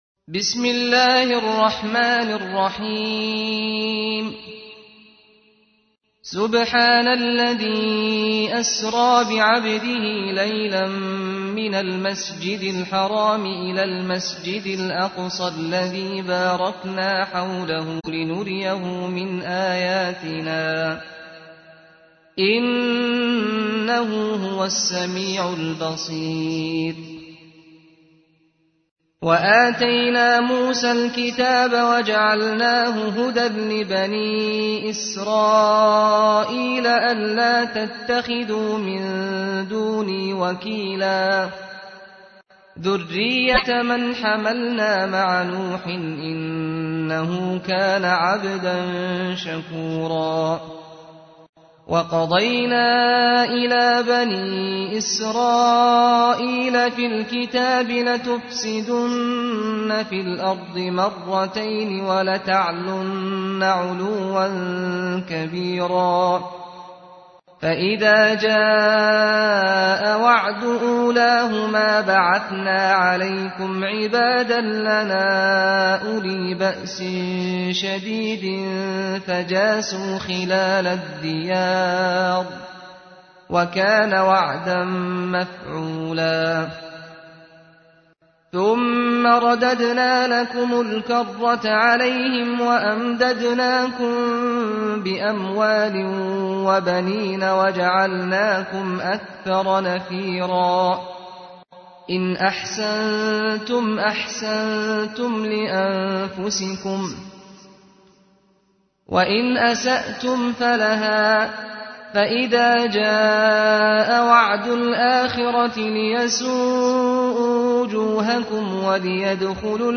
تحميل : 17. سورة الإسراء / القارئ سعد الغامدي / القرآن الكريم / موقع يا حسين